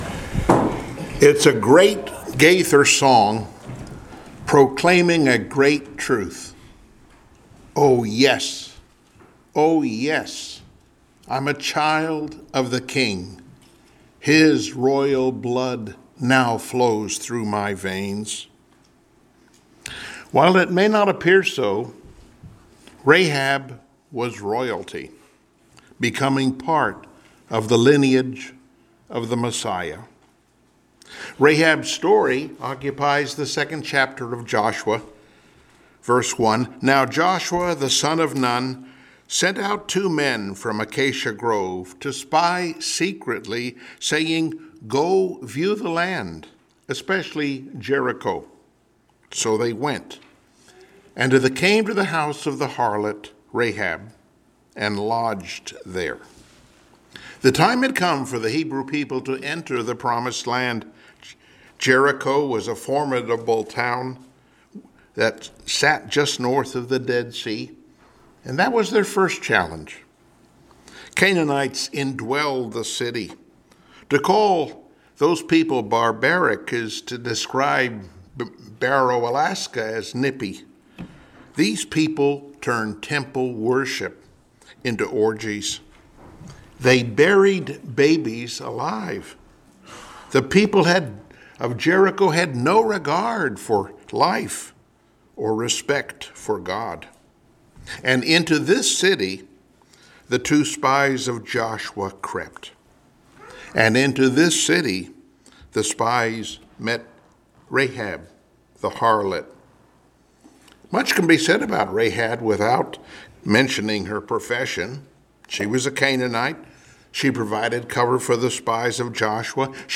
Joshua 2 Service Type: Sunday Morning Worship 1 John 3:2 Dear friends